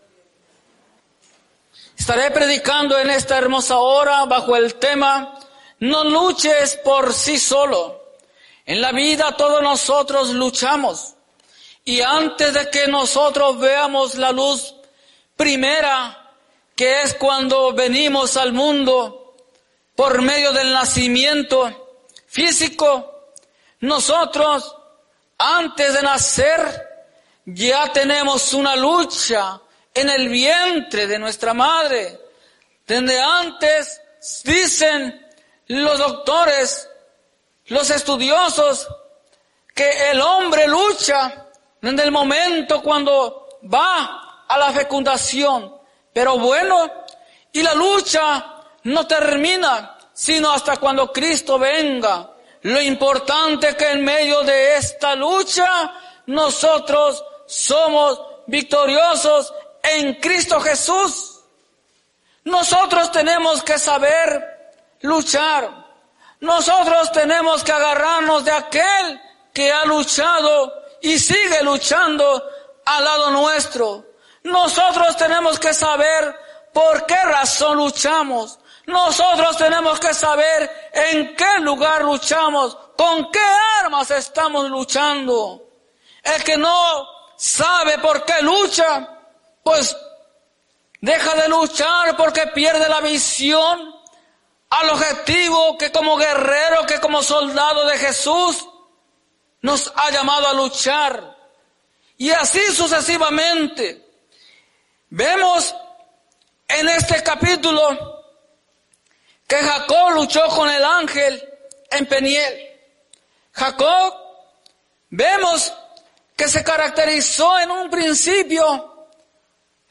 Predica
Norristown,PA